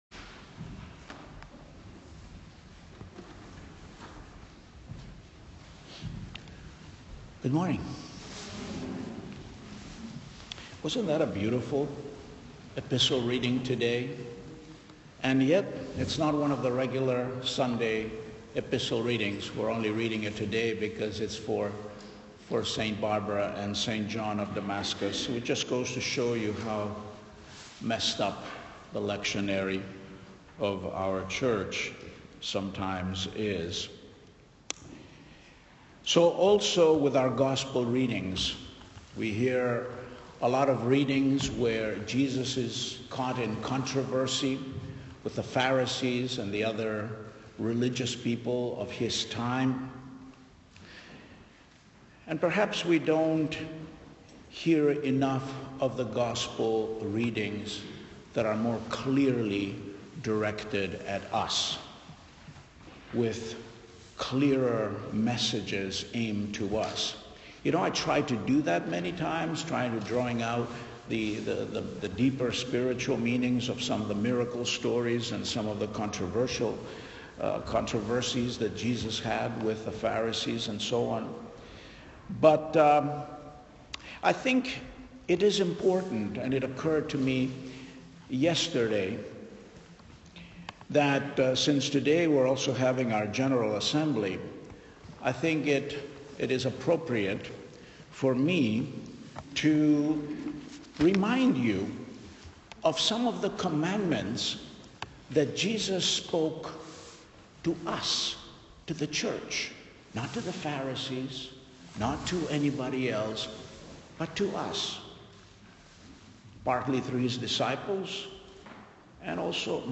The audio file contains the sermon.